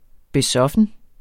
Udtale [ beˈsʌfən ]